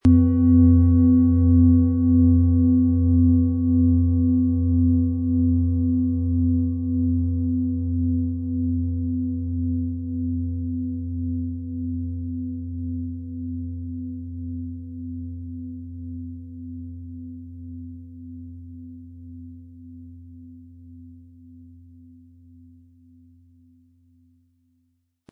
Planetenton 1
Diese tibetische Klangschale mit dem Ton von Sonne wurde von Hand gearbeitet.
Der richtige Schlegel ist umsonst dabei, er lässt die Klangschale voll und angenehm erklingen.
MaterialBronze